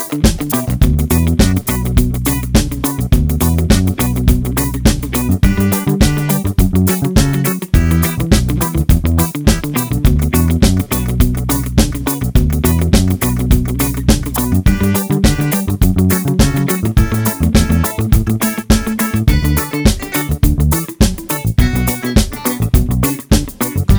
no Backing Vocals Reggae 3:51 Buy £1.50